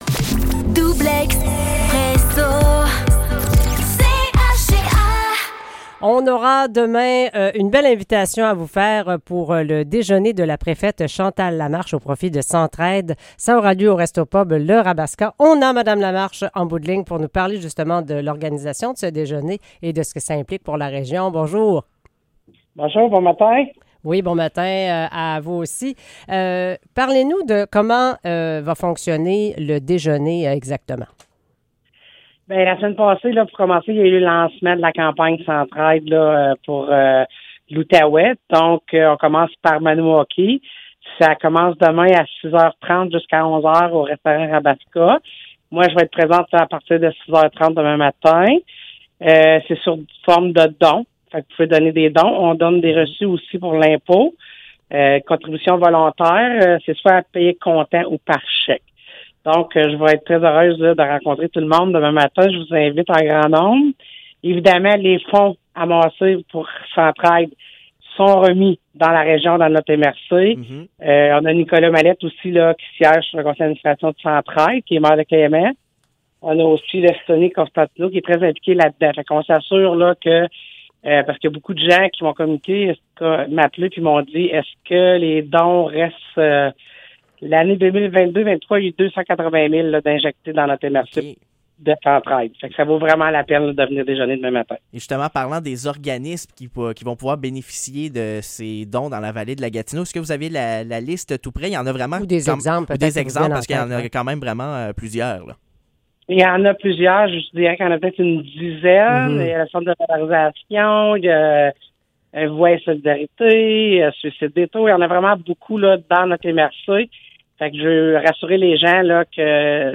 Entrevue avec Chantal Lamarche pour le déjeuner au profit de Centraide Outaouais